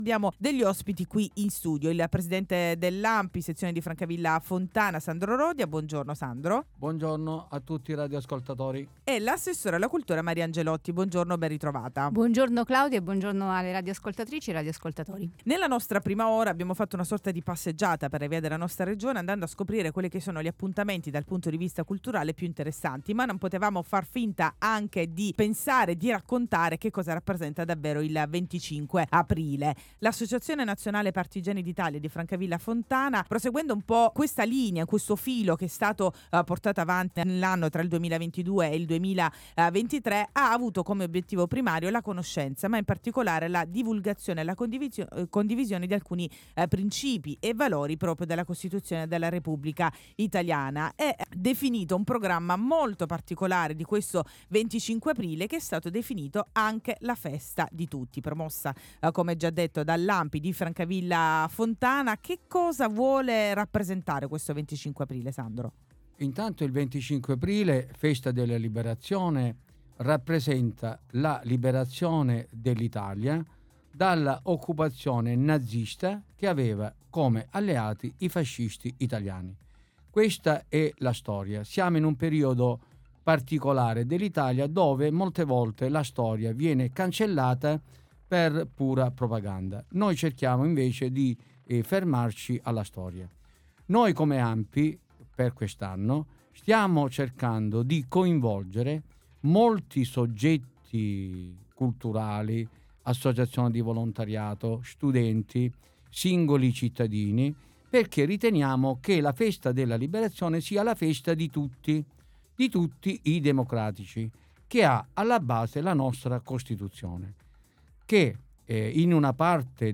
L’Associazione Nazionale Partigiani d’Italia di Francavilla Fontana, prosegue nell’attuazione di iniziative che hanno come obiettivo primario la conoscenza, la divulgazione e la condivisione dei principi e dei valori della Costituzione della Repubblica Italiana, ospite di Radio Antenna Sud per raccontare il fitto programma della giornata de l“25 Aprile – La Festa di Tutti”.